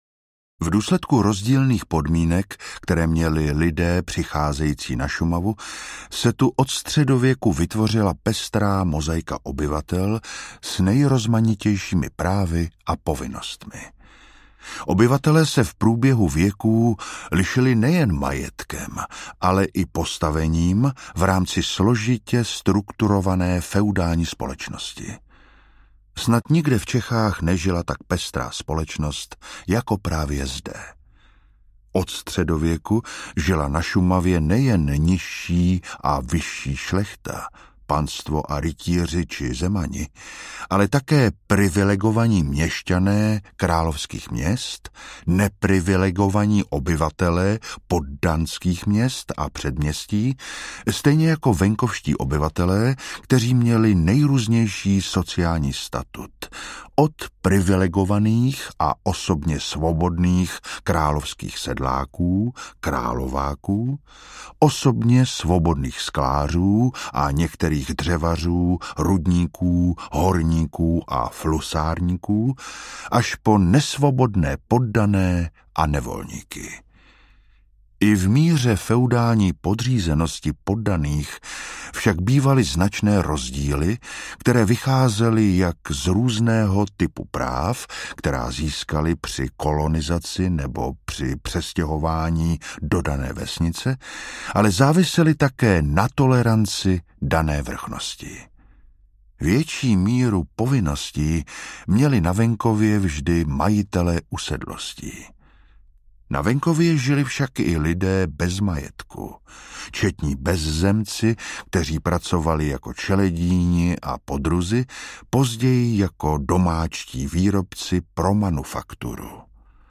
Život staré Šumavy audiokniha
Ukázka z knihy